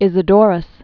(ĭzĭ-dôrəs) fl. sixth century AD.